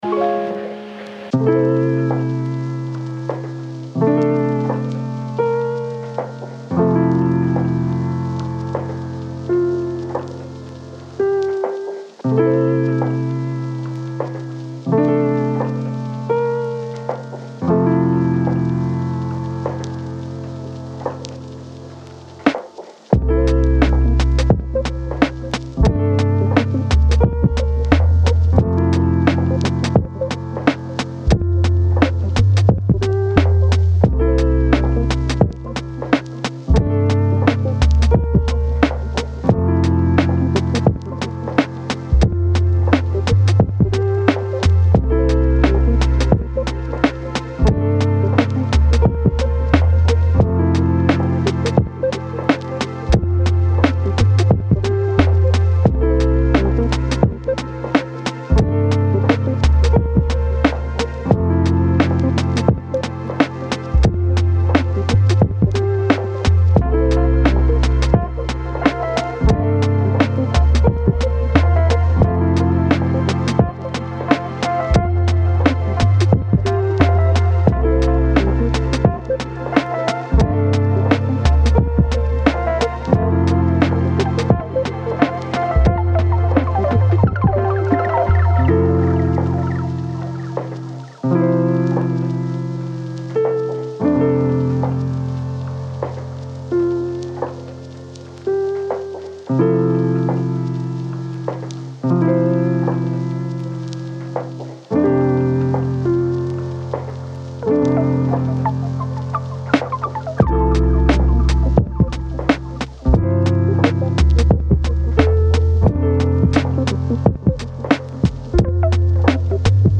is a massive collection of dreamy loops, one-shots, & MIDI.
10 Royalty Free Lofi Packs
Various BPMs
Demo